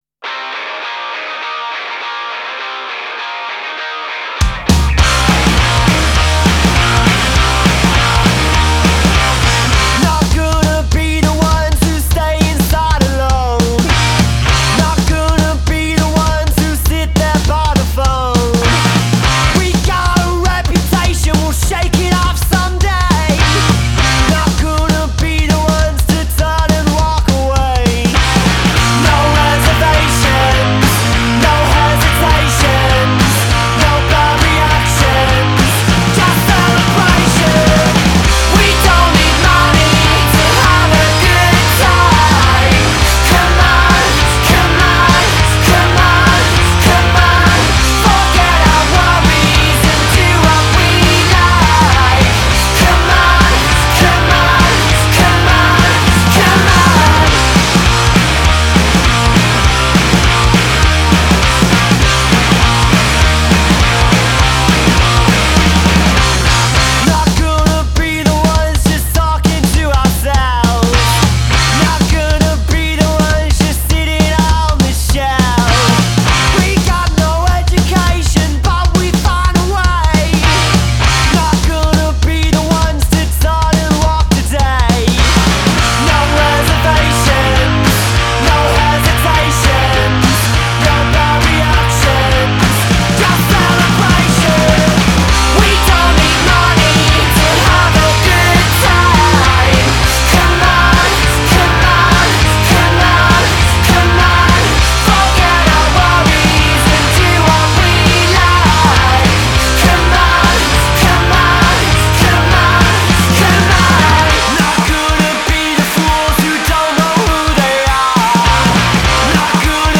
Canciones cortas y efectivas, pegajosas y directas.